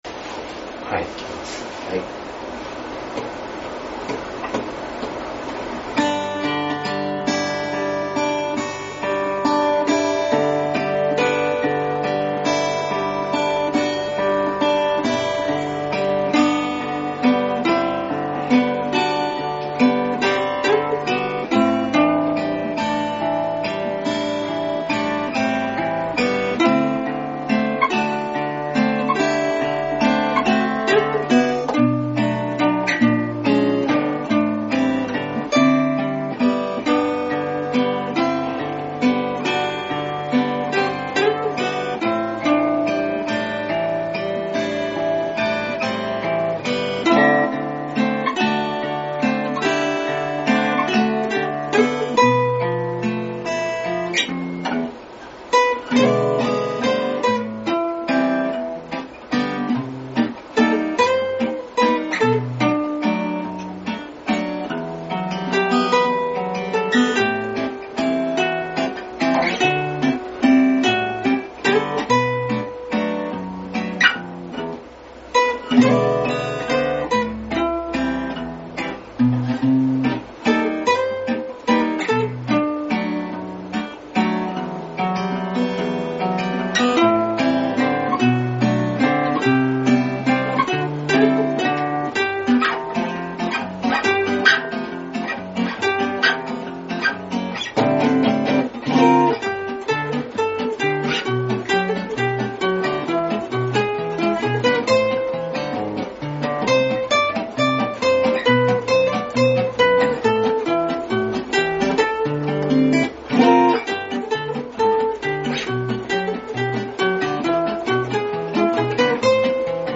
アコースティックギターインスト ゼロゼロヘブン 試聴あり
ムード・ミュージックのような肩の凝らない音楽を目指すアコースティックギターデュオゼロゼロヘブンです。
年末から新曲練習中。